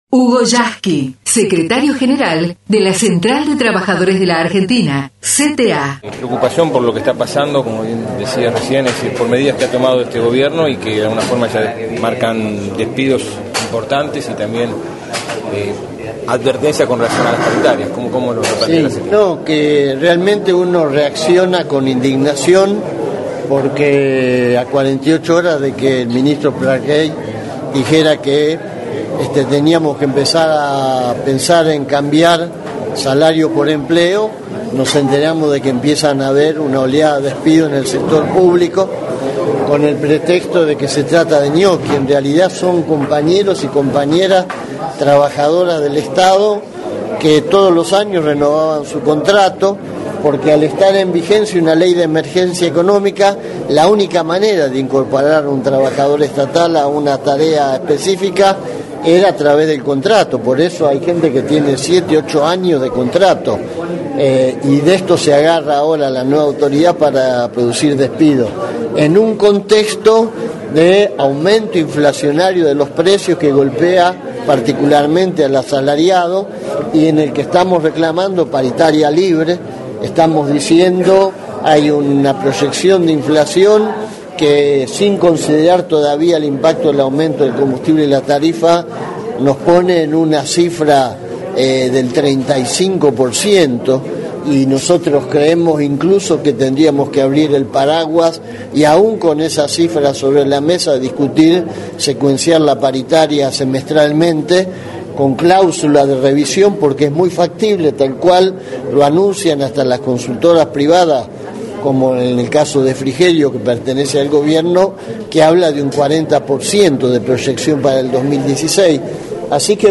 HUGO YASKY (rueda de prensa) PARITARIAS LIBRES